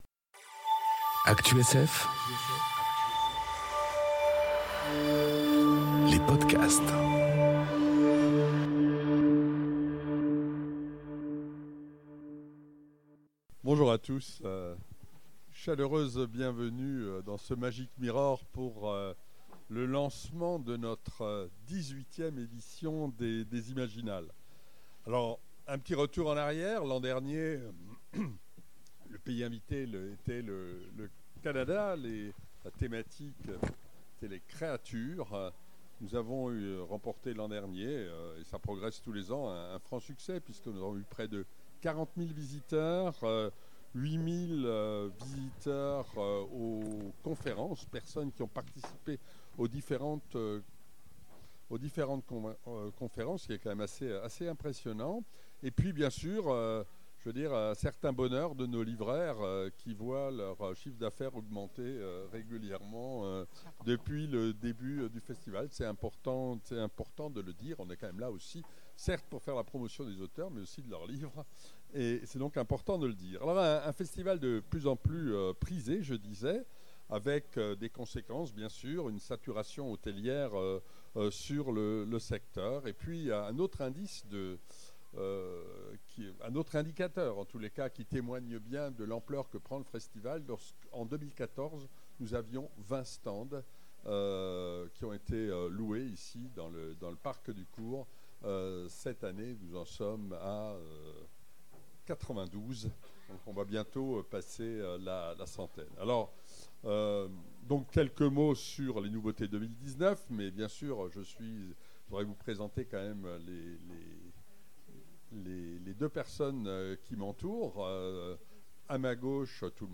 Découvrez le coup d'envoi des imaginales, enregistré en intégralité.